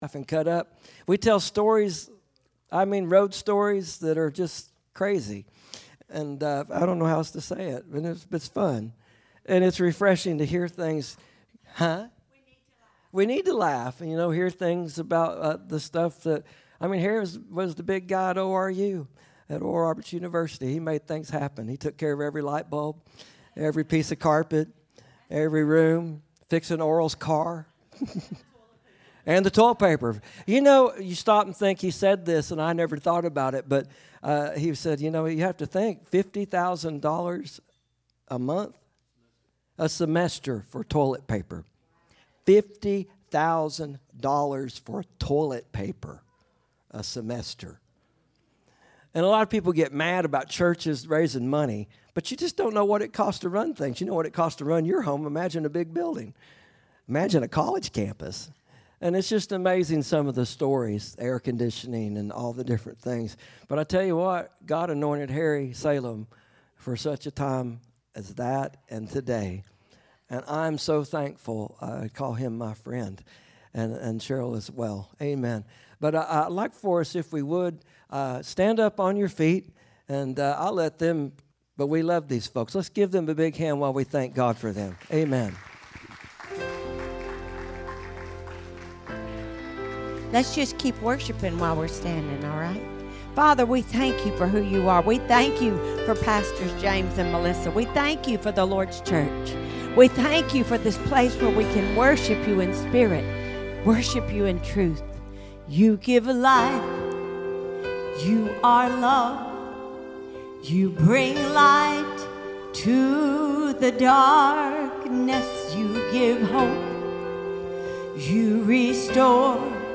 AM Service